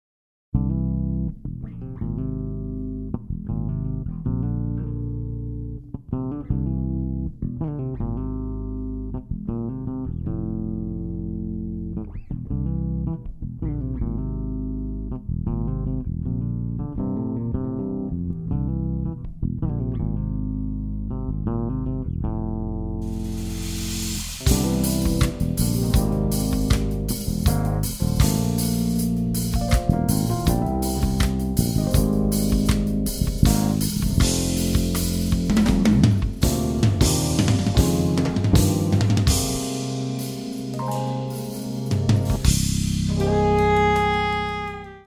bassist and composer
on drums
on keyboards
on alto saxophone